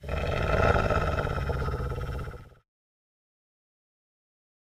wolf-growl
Category 🐾 Animals
animal animals bark dog growl pet snarl wolf sound effect free sound royalty free Animals